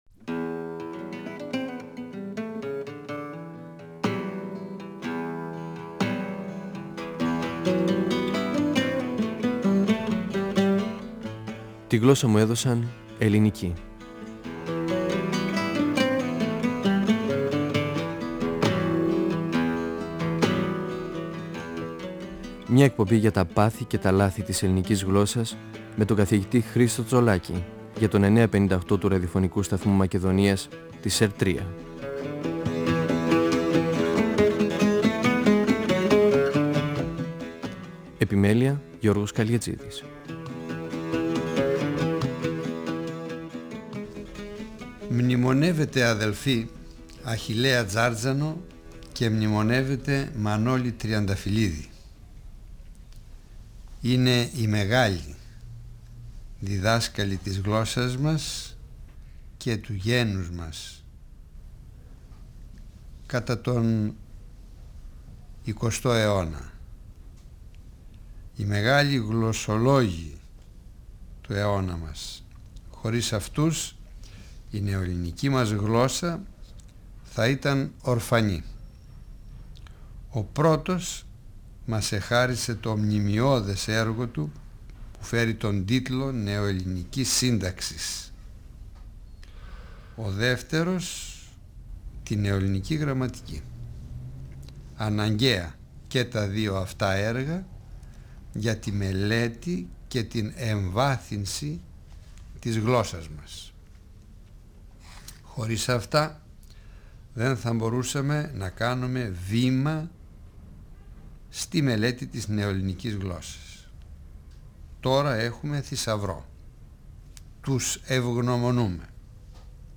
Ο γλωσσολόγος Χρίστος Τσολάκης (1935-2012) μιλά για τη συμβολή του Αχιλλέα Τζάρτζανου (1873-1946) στα θέματα της ελληνικής γλώσσας. Αναφέρεται στη ζωή του Α.Τ., στα πρόσωπα που συνέβαλαν στη διαμόρφωση του χαρακτήρα του και στο πού οφείλεται το φαινόμενο Αχιλλέας Τζάρτζανος.